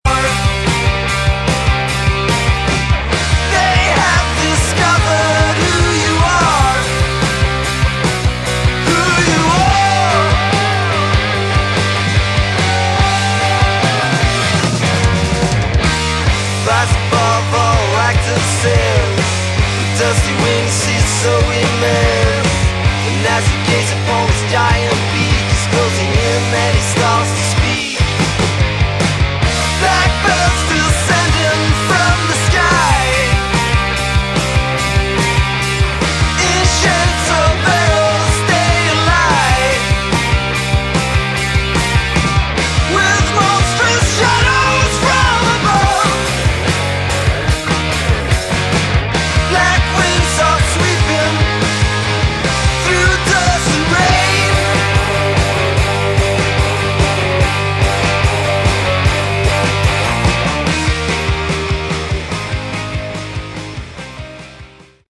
Category: Hard Rock
guitar, vocals
drums